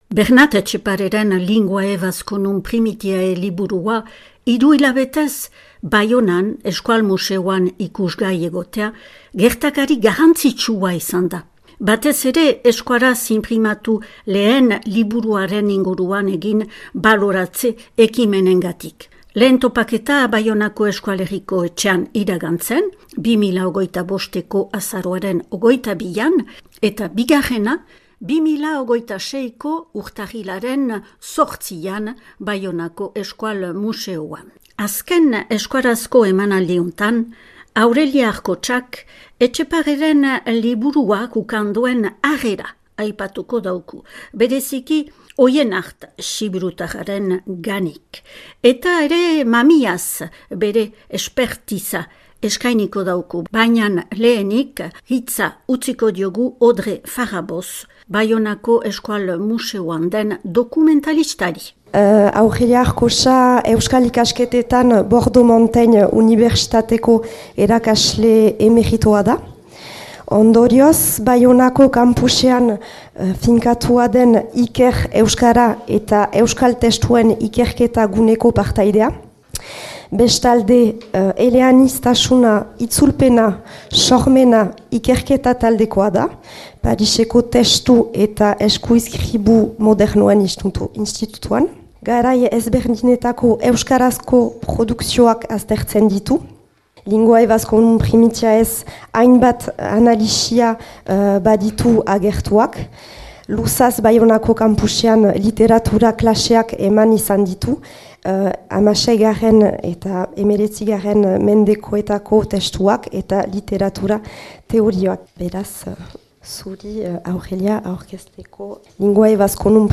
Euskaraz inprimatu lehen liburuaren inguruko topaketak, Euskal museoak antolaturik 2025eko azaroaren 22an Baionako Herriko Etxean.